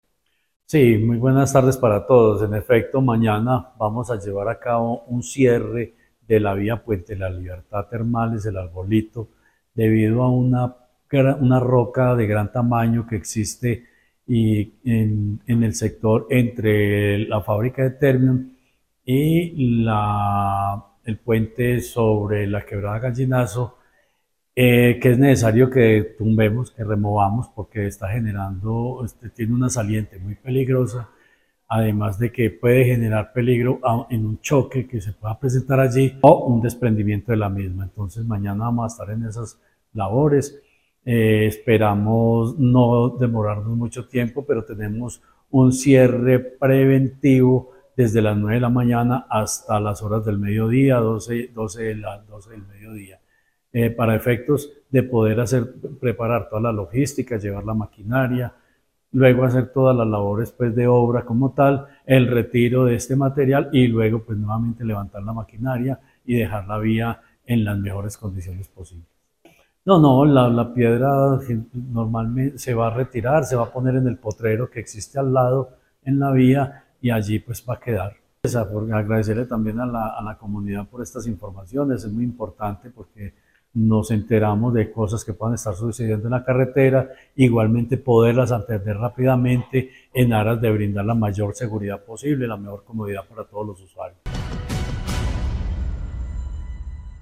Secretario de Infraestructura de Caldas, Jorge Ricardo Gutiérrez Cardona.
Jorge-Ricardo-Gutierrez-Cardona-Termales.mp3